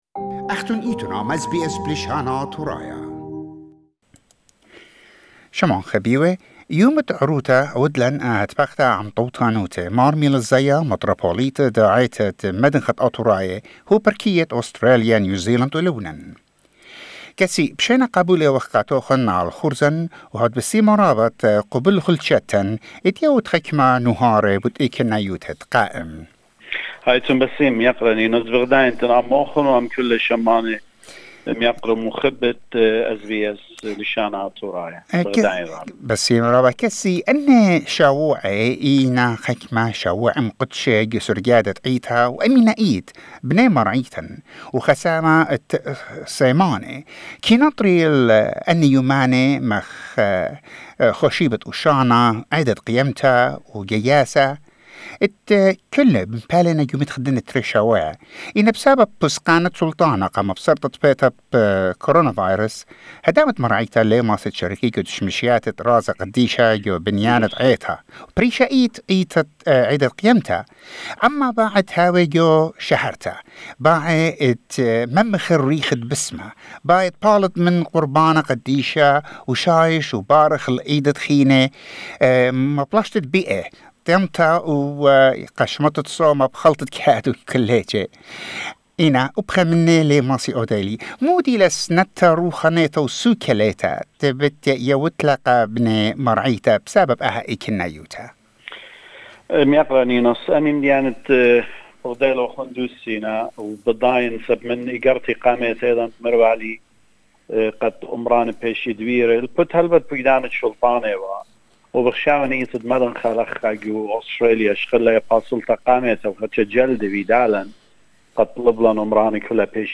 His Beatitude Mar Meelis Zaia in an interview with SBS Assyrian, delivered some messages about the COVID-19 situation and matters that concern the church. He asked worshipers to be patient and pray so we can pass over this crisis.